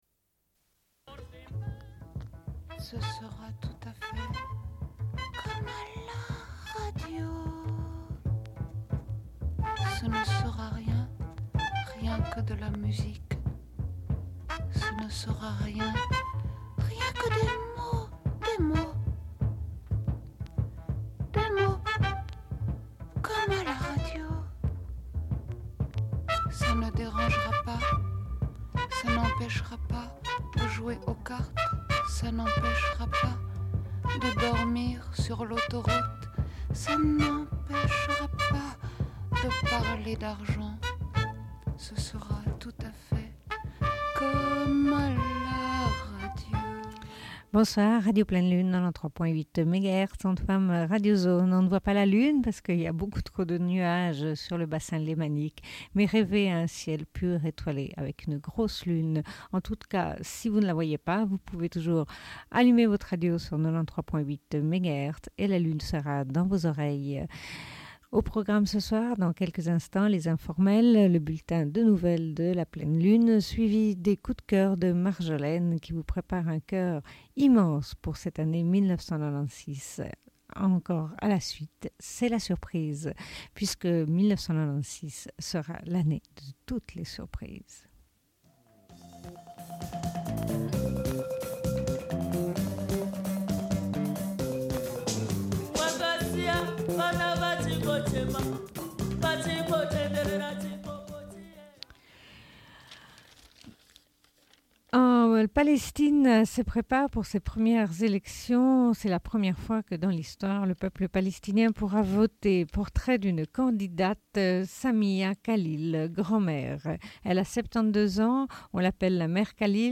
Bulletin d'information de Radio Pleine Lune du 17.01.1996 - Archives contestataires
Une cassette audio, face B